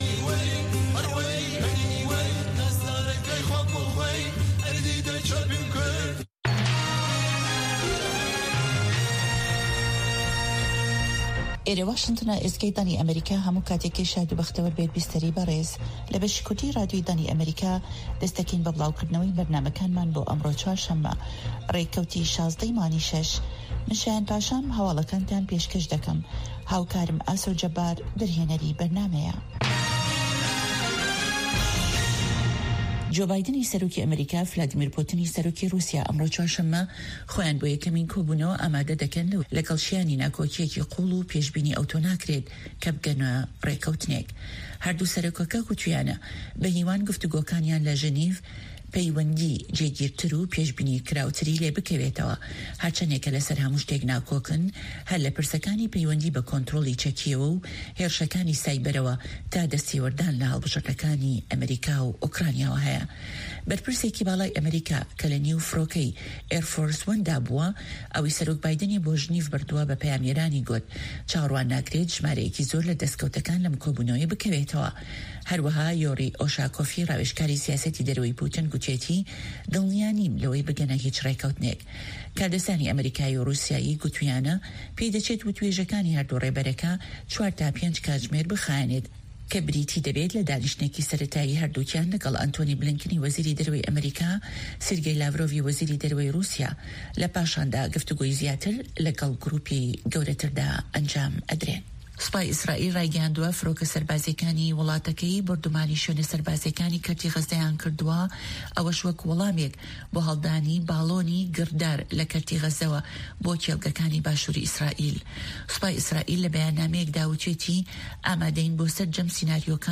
هه‌واڵه‌کان، ڕاپـۆرت، وتووێژ، به‌رنامه‌ی هه‌فتانه‌ی "ڕه‌هه‌ندی پـێشـهاته‌کان"